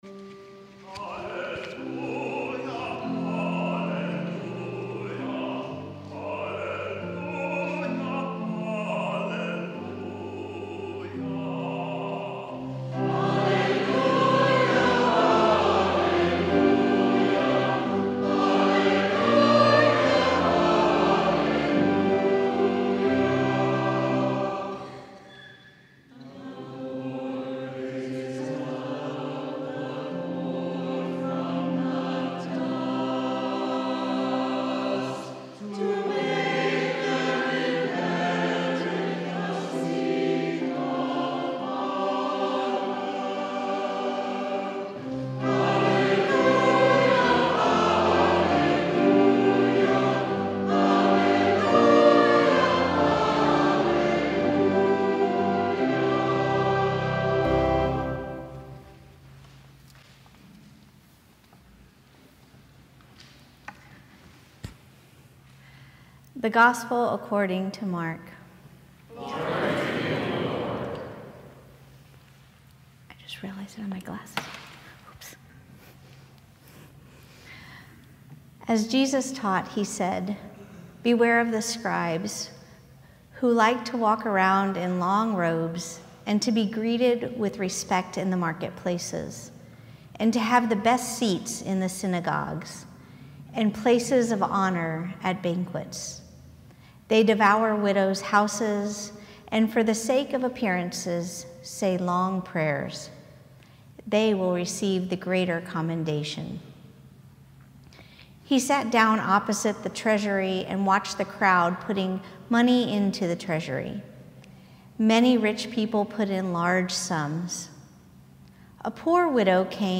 Sermon from the Twenty-fifth Sunday After Pentecost